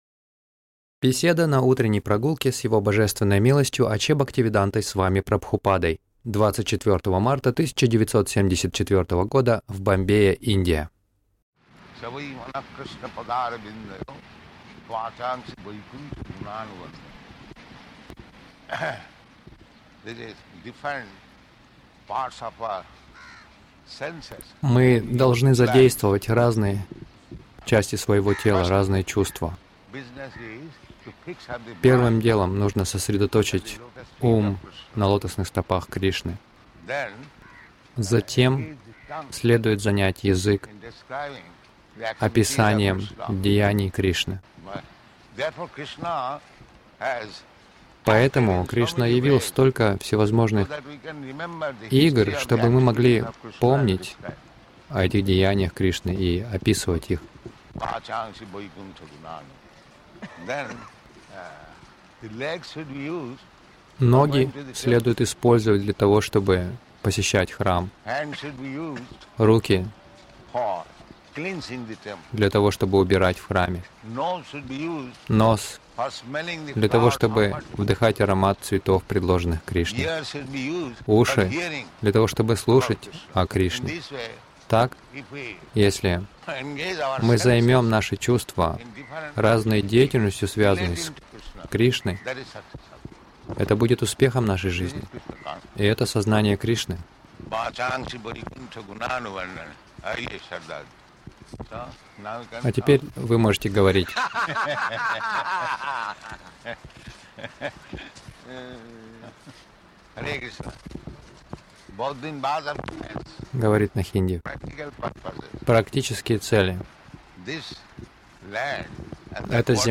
Утренние прогулки — Отдать плоды Кришне
Милость Прабхупады Аудиолекции и книги 24.03.1974 Утренние Прогулки | Бомбей Утренние прогулки — Отдать плоды Кришне Загрузка...